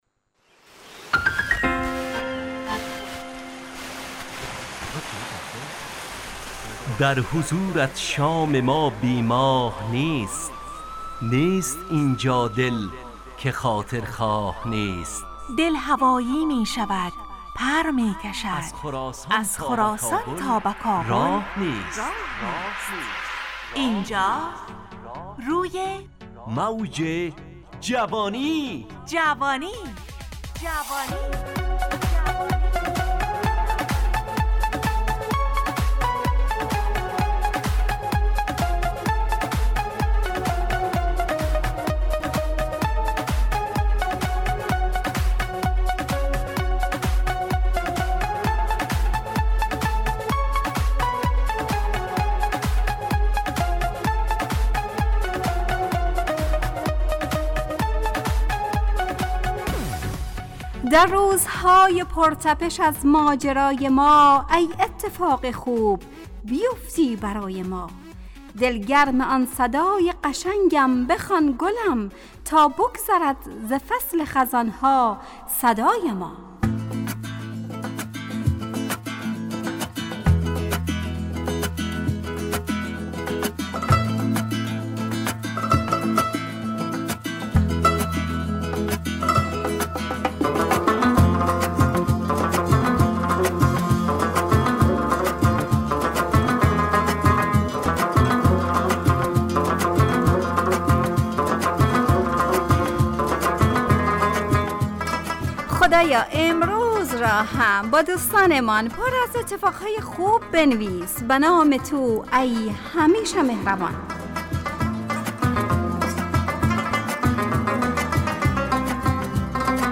روی موج جوانی، برنامه شادو عصرانه رادیودری.
همراه با ترانه و موسیقی مدت برنامه 55 دقیقه . بحث محوری این هفته (دوستی) تهیه کننده